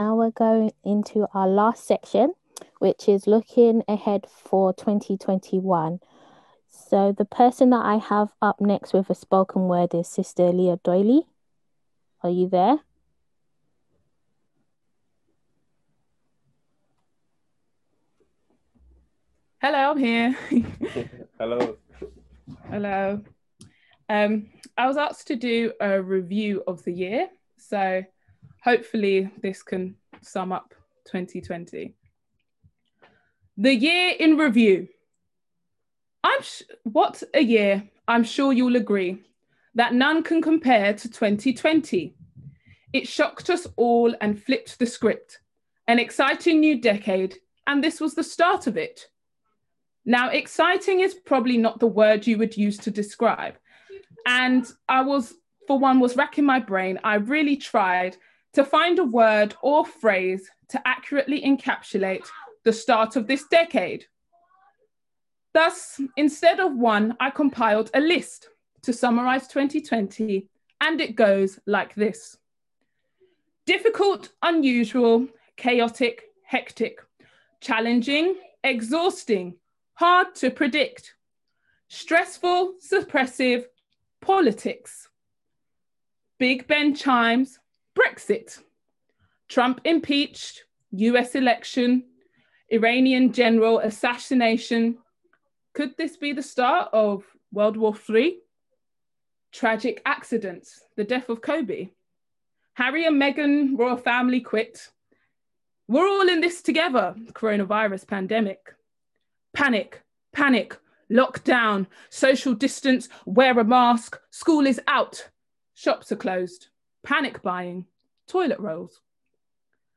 on 2021-01-01 - End of Year Service 31.12.20